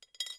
coin_glass_1.ogg